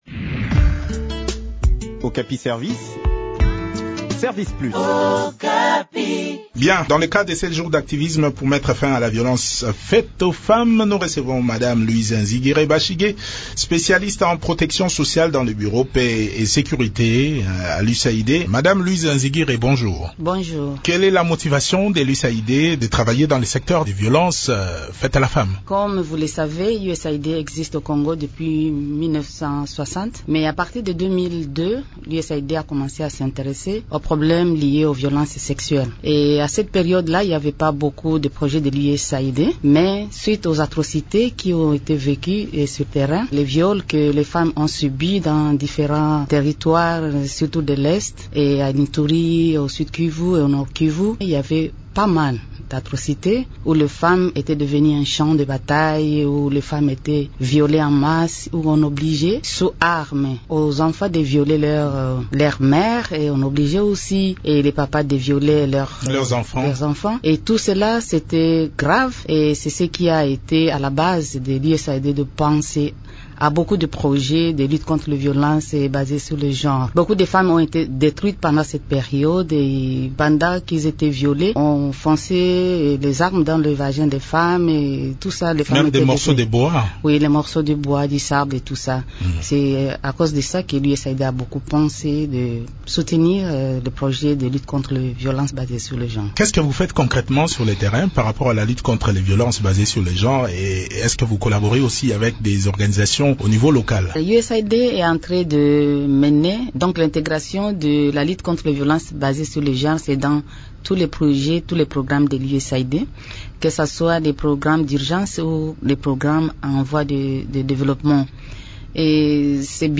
Le point sur les différents projets appuyés par l’USAID au Nord et Sud-Kivu dans cet entretien